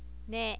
ne.wav